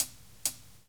56BRUSHHH -L.wav